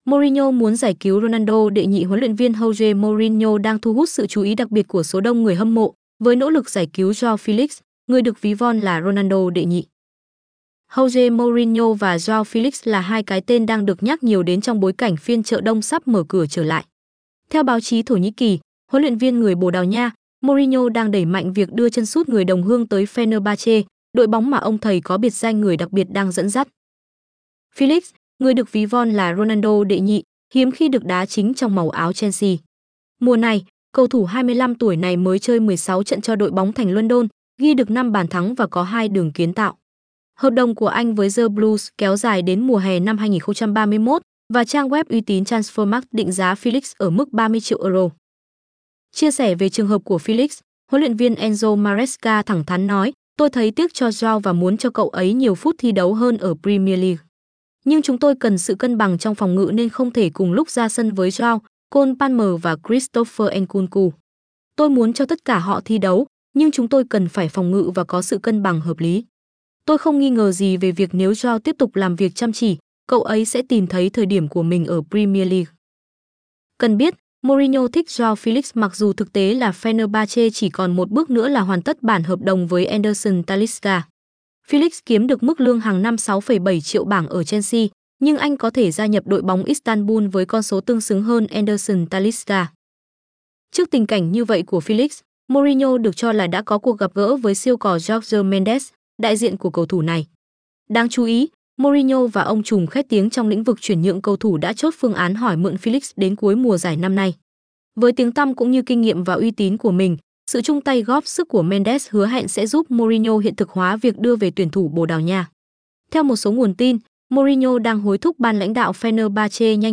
hn_female_ngochuyen_full_48k-fhg.mp3